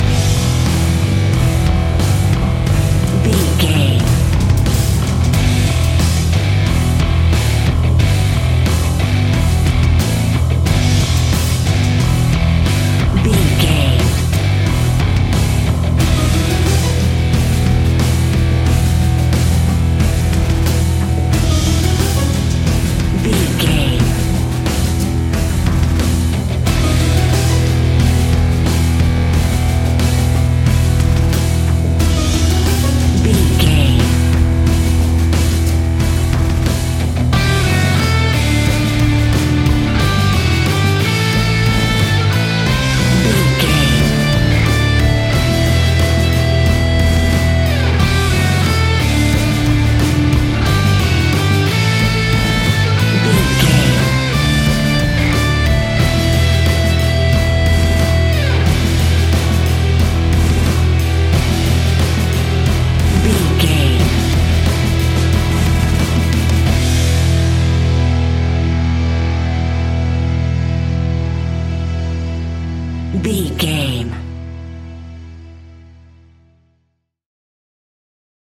Epic / Action
Fast paced
Aeolian/Minor
hard rock
guitars
instrumentals
Heavy Metal Guitars
Metal Drums
Heavy Bass Guitars